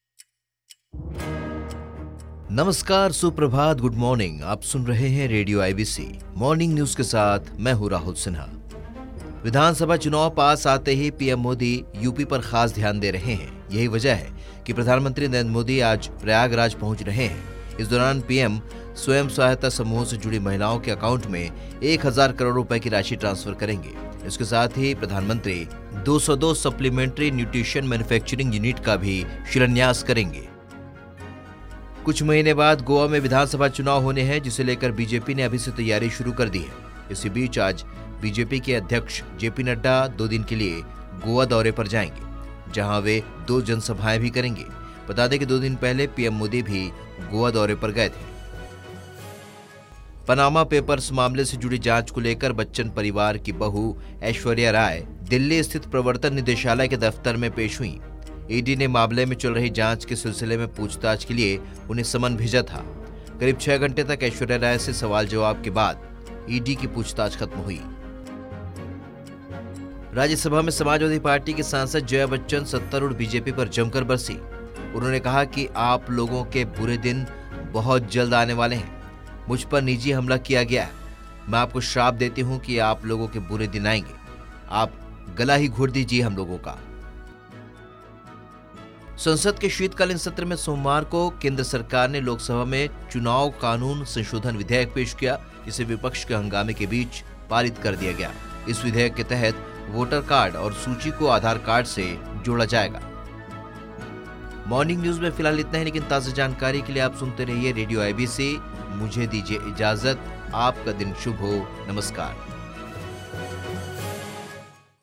Latest news podcast 2021